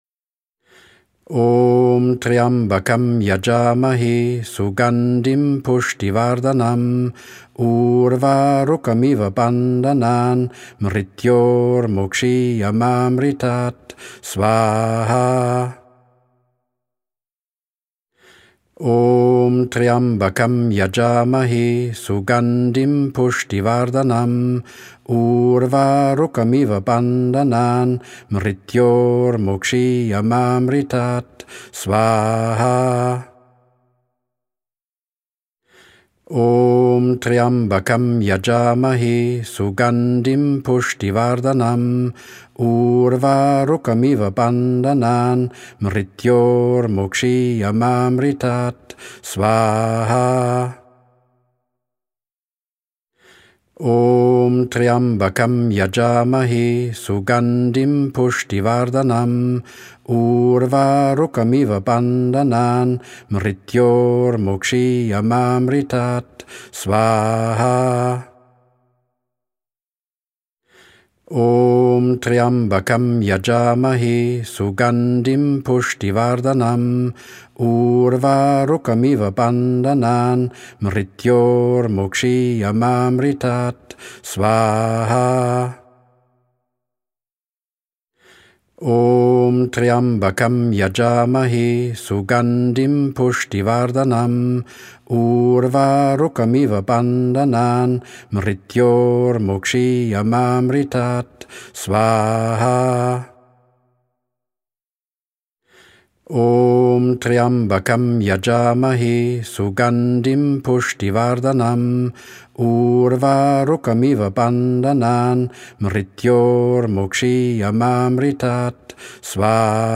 Dabei wird das Heilungs-Mantra Tryambakam gesungen, während das Feuer brennt.
Agnihotra-Und-Yagna-Mantras-04-Om-Tryambakam-9-Mal.mp3